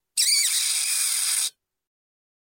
В коллекции есть разные варианты: от шороха лапок до стрекотания.
Звук писка таракана